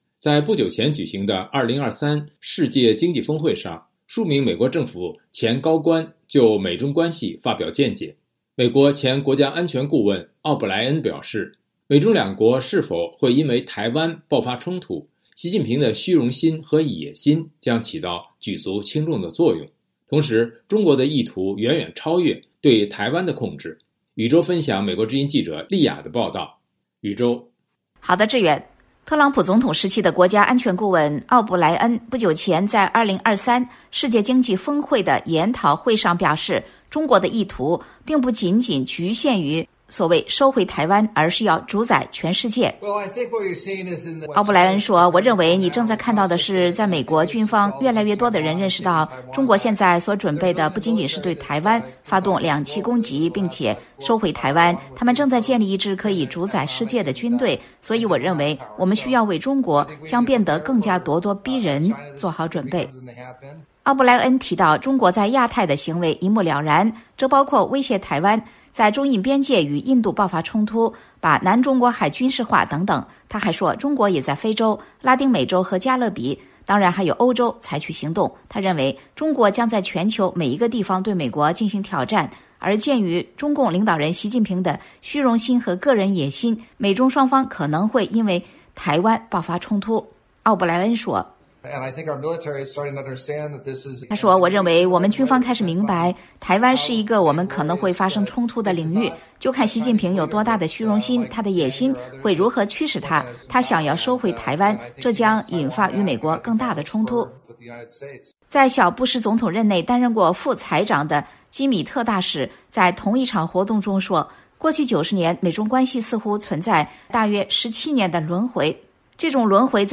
在不久前举行的2023世界经济峰会上，数名美国政府前高官就美中关系发表见解。美国前国家安全顾问奥布莱恩(Robert O’Brien)表示，美中两国是否会因为台湾爆发冲突，习近平的“虚荣心和野心”将起到举足轻重的作用，同时，中国的意图远远超越对台湾的控制。